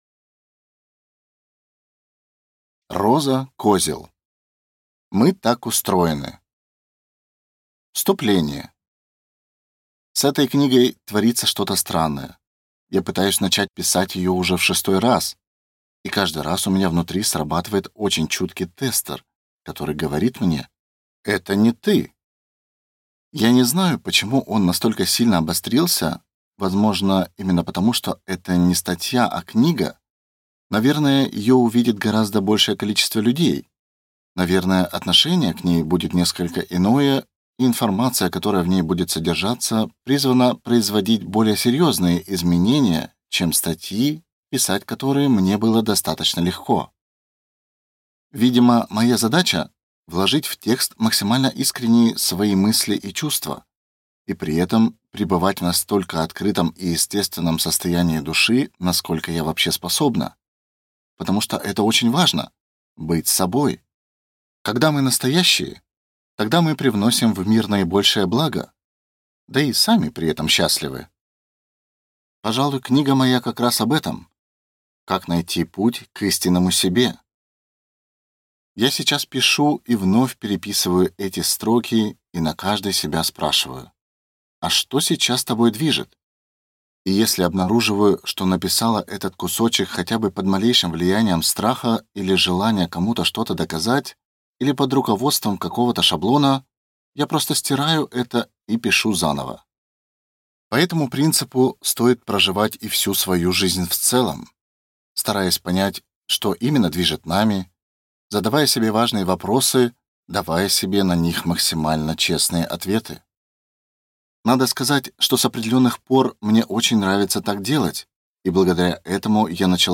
Аудиокнига Мы так устроены!